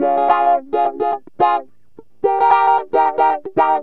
GTR 9 A#M110.wav